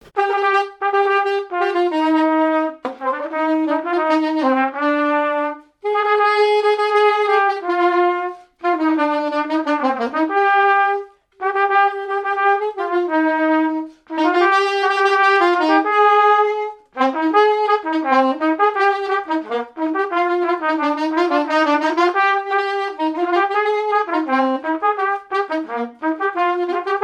circonstance : fiançaille, noce
répertoire de marches de noces
Pièce musicale inédite